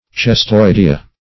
Cestoidea \Ces*toid"e*a\ (s[e^]s*toid"[-e]*[.a]), n. pl. [NL.,